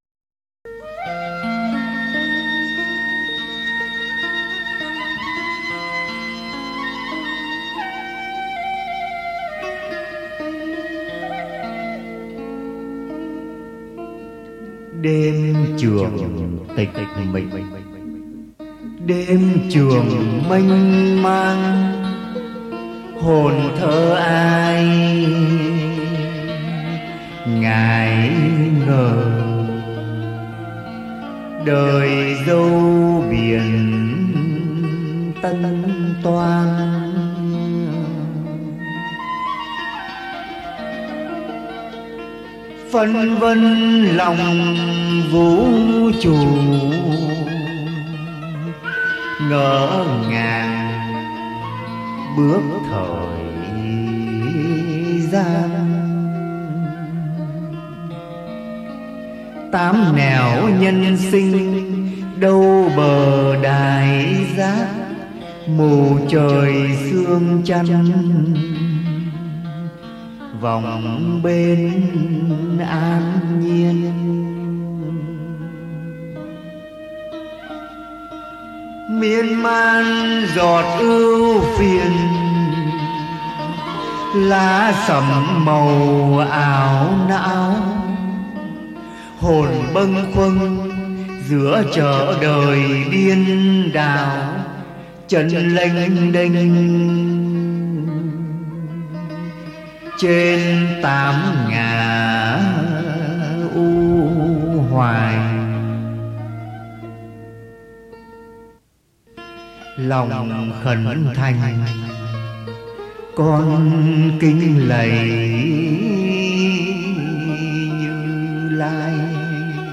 Tân Nhạc